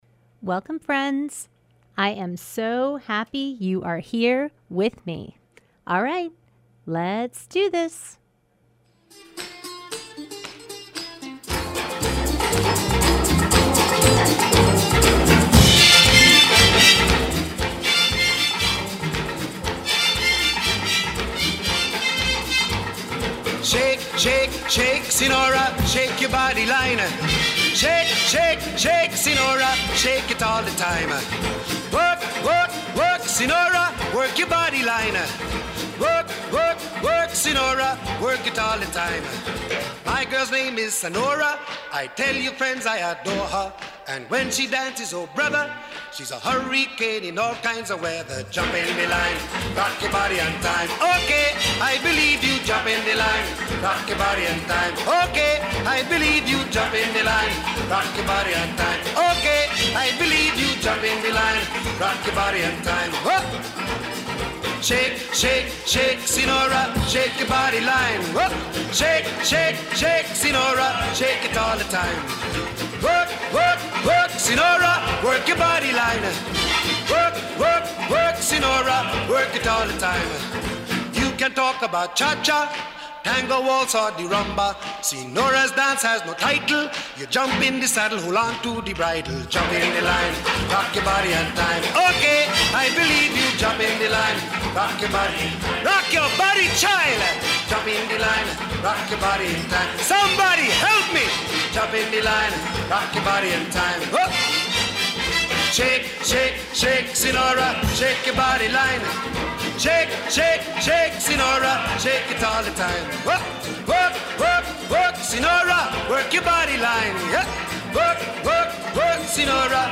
Broadcast live every Thursday evening from 6:30 to 8pm on WTBR